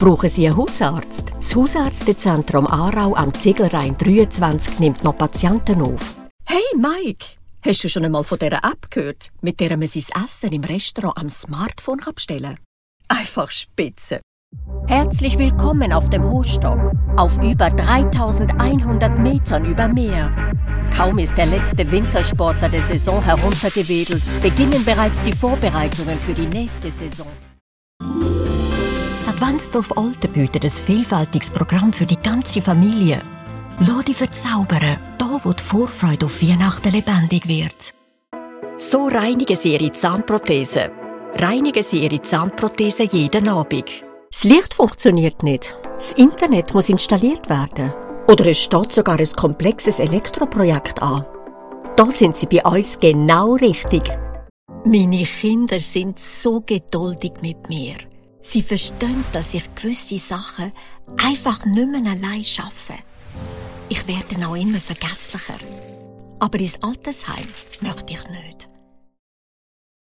Seasoned and versatile, with a reassuring tone.